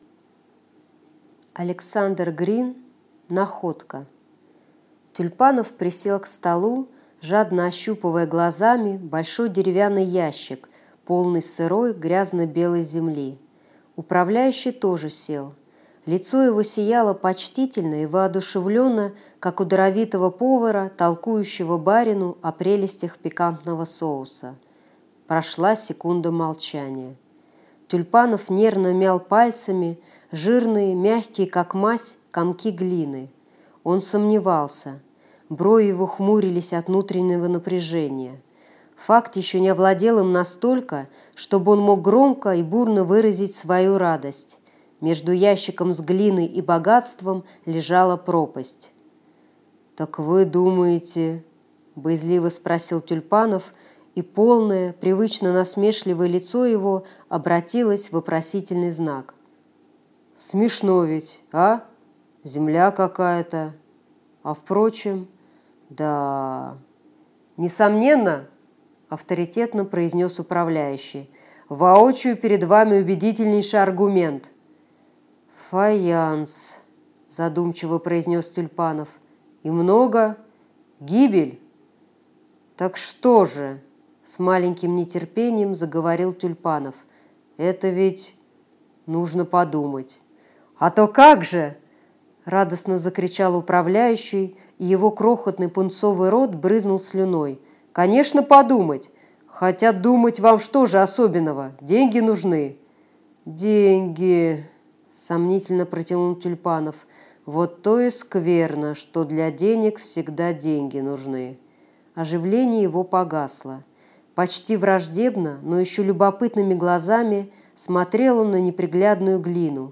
Аудиокнига Находка | Библиотека аудиокниг
Прослушать и бесплатно скачать фрагмент аудиокниги